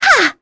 Talisha-Vox_Attack3.wav